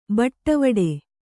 ♪ baṭavāḍe